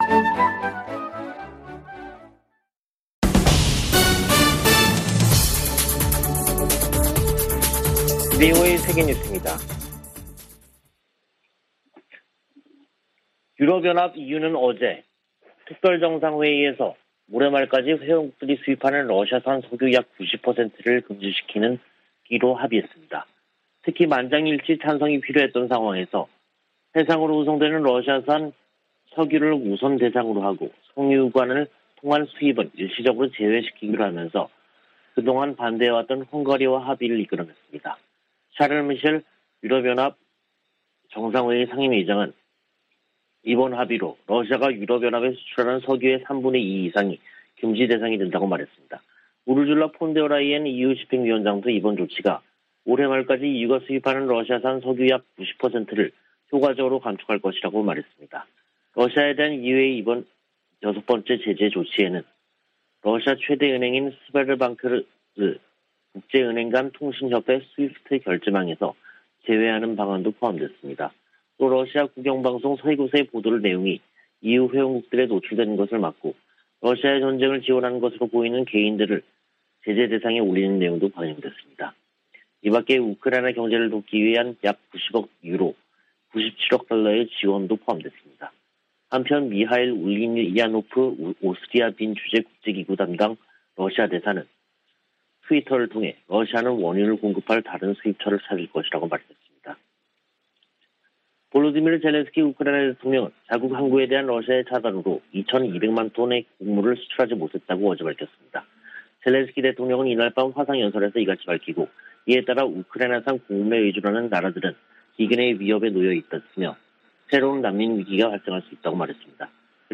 VOA 한국어 간판 뉴스 프로그램 '뉴스 투데이', 2022년 5월 31일 3부 방송입니다. 조 바이든 미국 대통령이 메모리얼데이를 맞아 미군 참전 용사들의 희생을 기리고, 자유민주주의의 소중함을 강조했습니다. 미 연방 상원의원이 메모리얼데이를 맞아 한국전쟁에서 숨진 미 육군 병장의 공로를 기렸습니다.